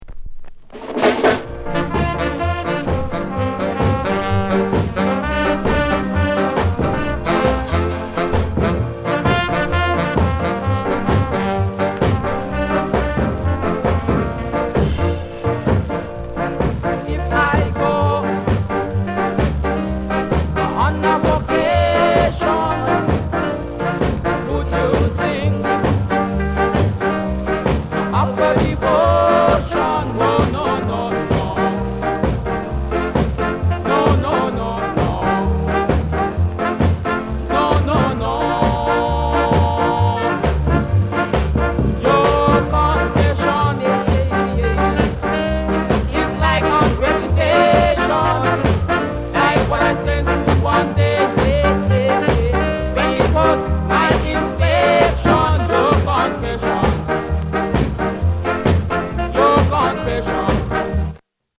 AUTHENTIC SKA